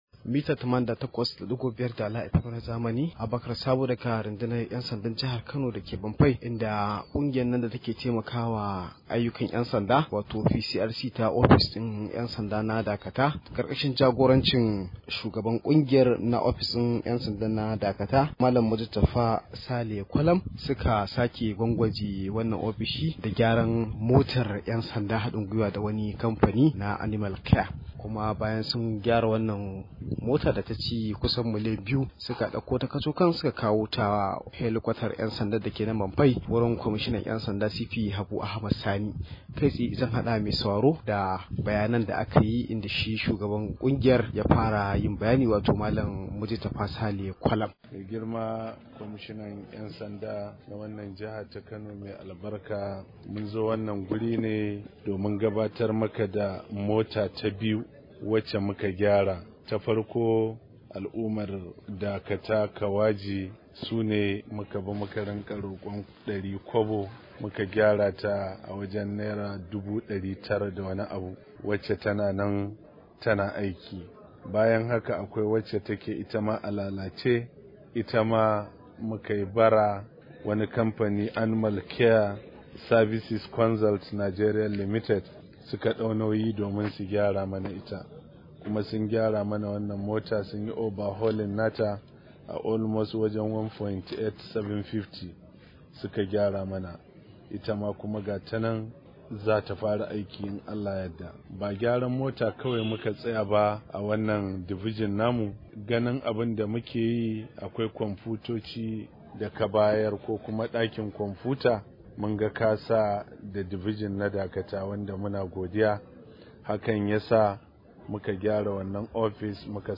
Rahoto: Kungiyar PCRC ta kashe miliyan 2 domin gyaran motar ‘yan sanda a Kano